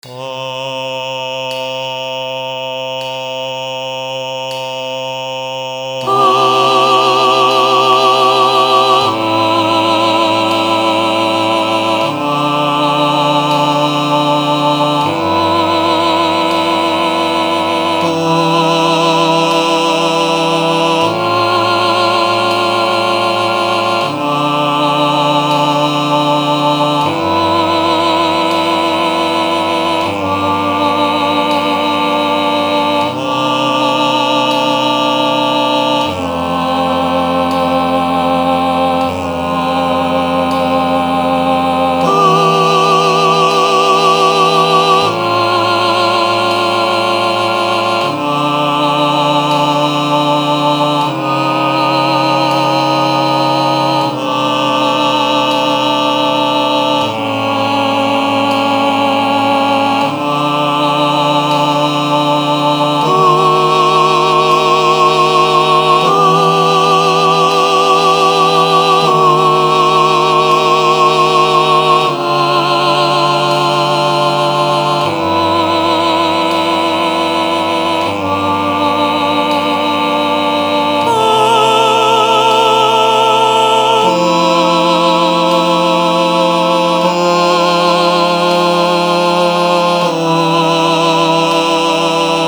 Soprano Emphasis
MP3 with Soprano Emphasis and Cymbal